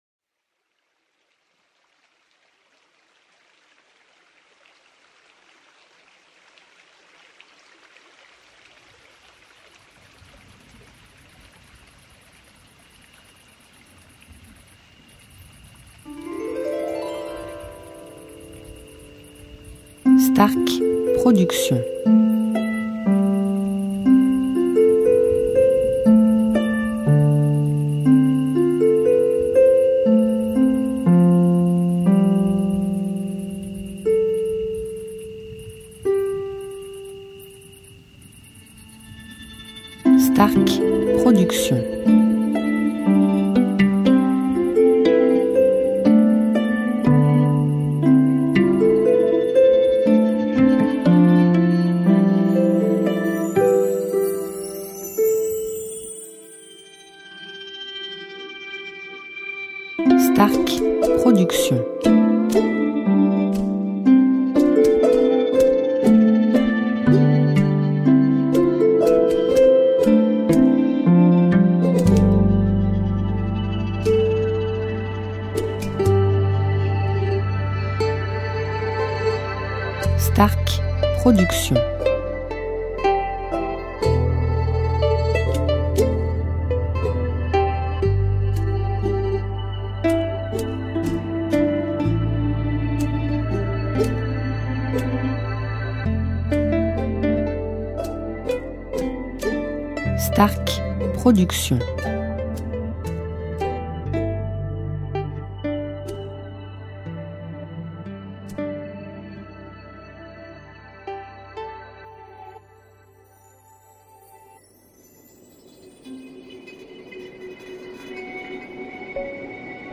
style Californien durée 1 heure